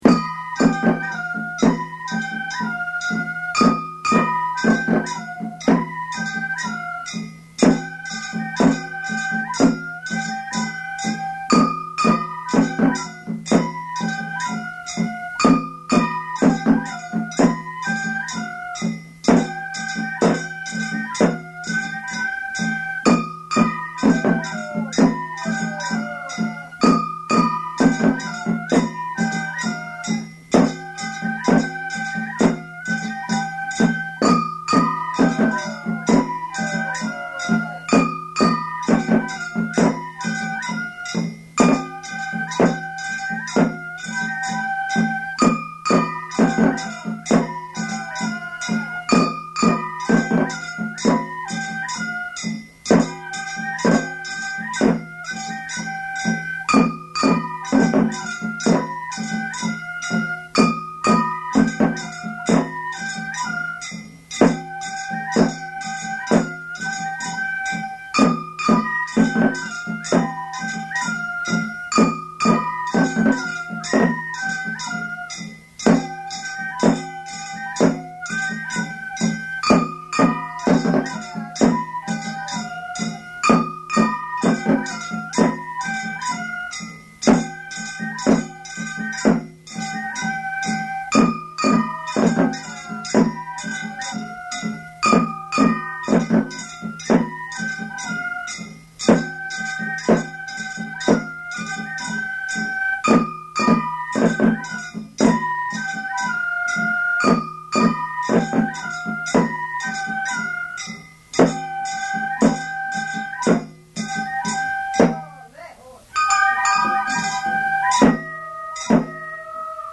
１５秒位が１クールの繰り返しで単調ではあるが、演奏している側は１時間やっても飽きない不思議な魅力を持つ曲。
昭和62年11月1日　京都太秦　井進録音スタジオ